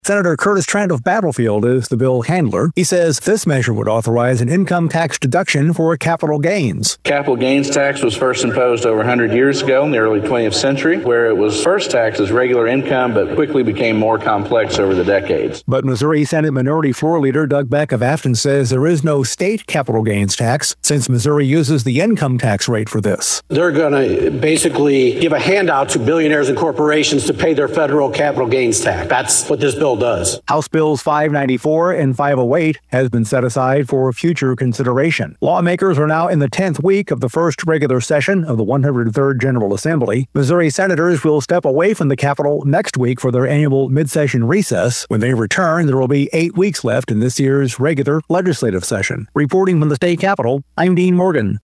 Senate reporter